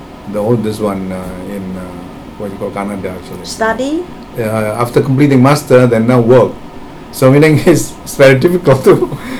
S1 = Taiwanese female S2 = Indonesian male Context: S2 is discussing where his children are and what they are doing.
Intended Word: now Heard as: no (now) Discussion: S1 was uncertain whether this was no or now .